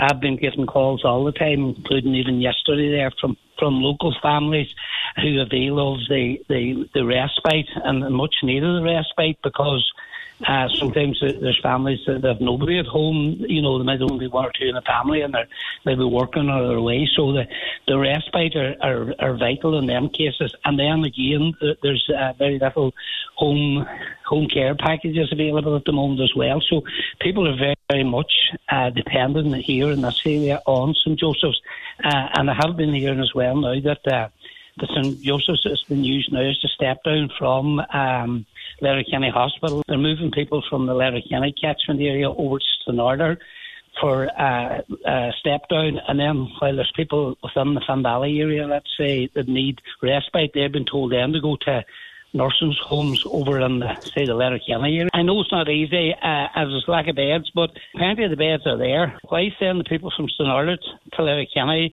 On today’s Nine til Noon Show, Cllr McGowan said this is an issue that is deeply affecting people in the Finn Valley: